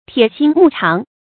鐵心木腸 注音： ㄊㄧㄝ ˇ ㄒㄧㄣ ㄇㄨˋ ㄔㄤˊ 讀音讀法： 意思解釋： 見「鐵心石腸」。